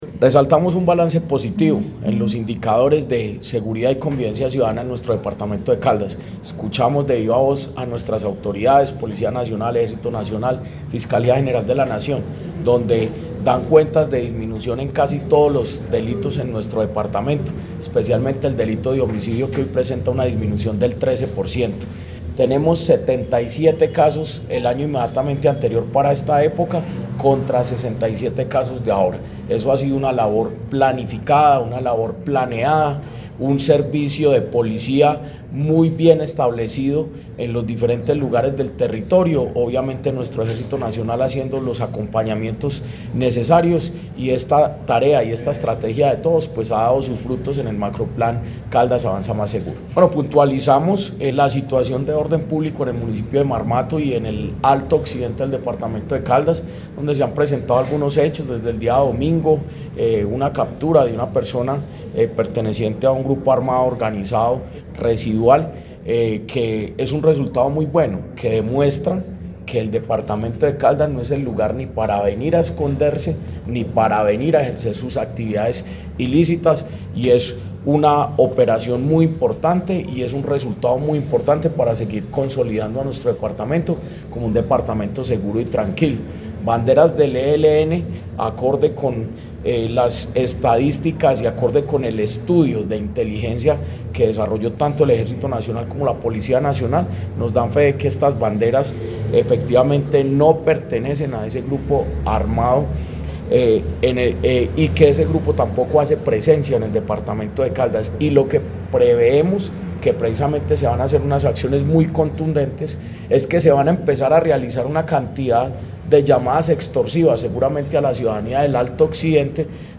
Jorge-Andres-Gomez-Escudero-secretario-de-Gobierno-de-Caldas-Sexto-consejo-de-seguridad.mp3